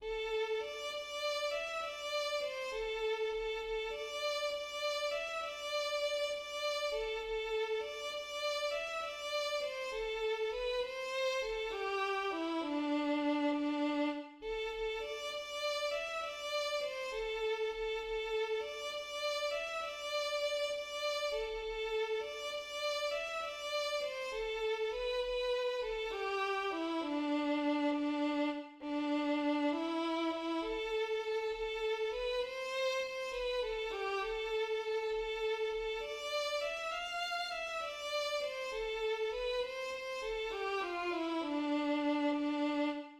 % add audio player \language "english" % notation of accidentals \relative c' % relative pitch coding { \set Staff.midiInstrument=#"violin" \key c \major \time 3/4 %\tempo not indicated %\cadenzaOn % enter barlines manually: auto sets wrong ones a'4 d8 d4 e8 % 1 d4 c8 a4 a8 % 2 a8 d4 d4 e8 % 3 d4.( d4) a8 % 4 a4 d8 d4 e8 % 5 d4 c8 a4 b8 % 6 c4 a8 g4 e8 % 7 d4.( d4) r8 % 8 a'8 a8 d8 d4 e8 % 9 d4 c8 a4( a8) % 10 a4 d8 d4 e8 % 11 d4.( d4) a16 a16 % 12 a4 d8 d4 e8 % 13 d4 c8 a4 b8 % 14 b4 a8 g4 e8 % 15 d4.( d4) r8 % 16 d4. e4 e8 % 17 a4.( a4) b8 % 18 c4 c8 b8[ a8 g8] % 19 a4.( a4) a8 % 20 d4 e8 f4 e8 % 21 d4 c8 a4 b8 % 22 c8[ c8 a8] g8 f8 e8 % 23 d4.( d4) r8 \bar "||" }